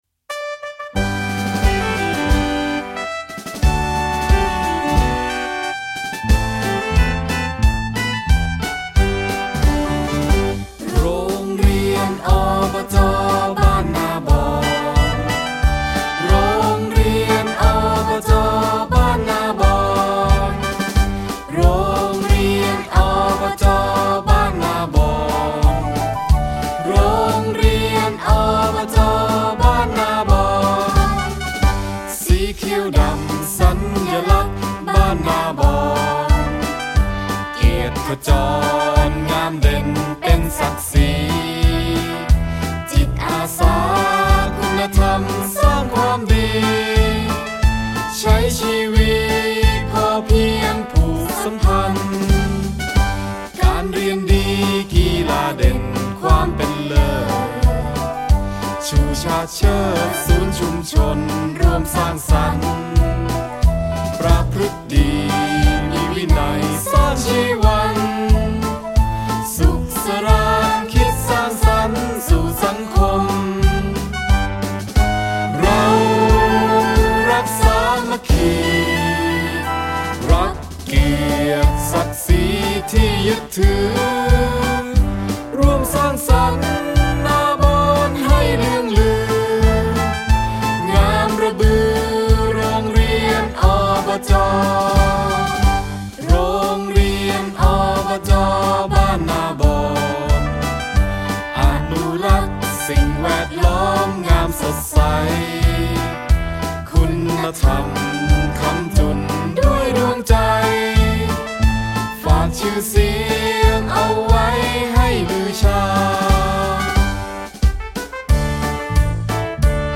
เพลงมาร์ชโรงเรียน อบจ.บ้านนาบอน